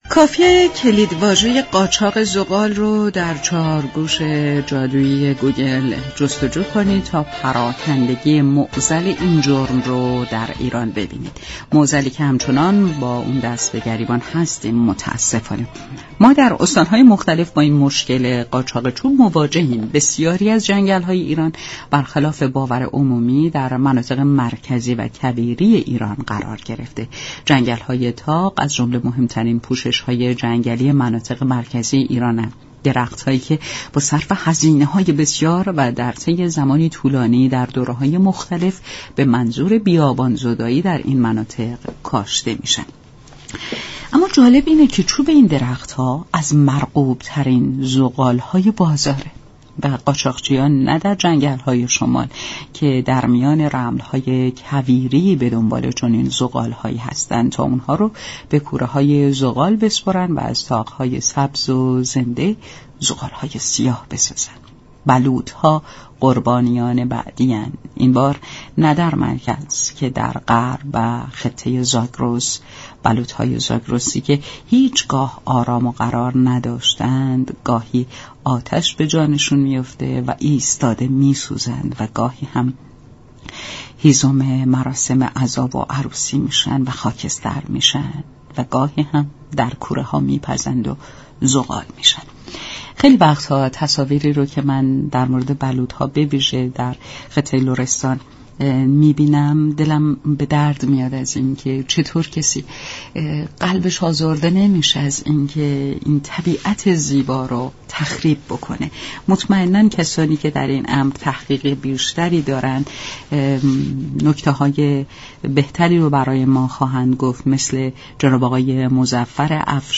این گفت و گو را در ادامه باهم می شنویم دریافت فایل تنظیم كننده